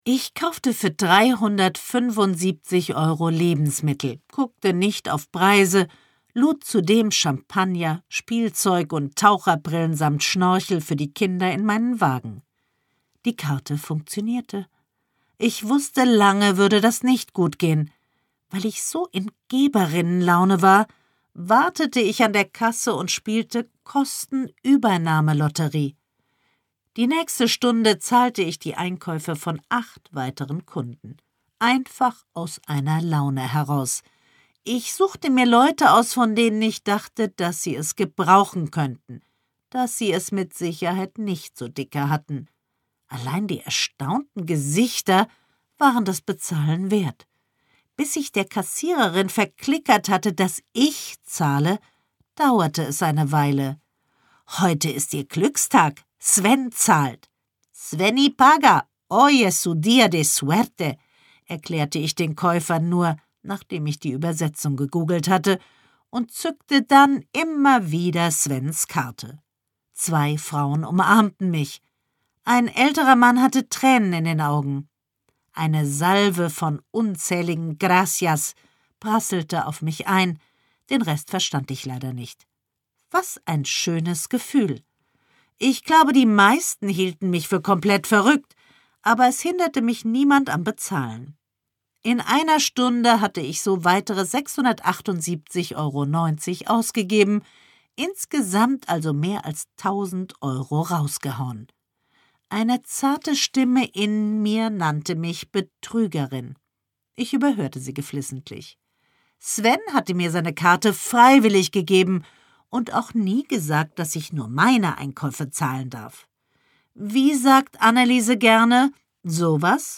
Susanne Fröhlich (Sprecher)
Gekürzte Lesung mit Susanne Fröhlich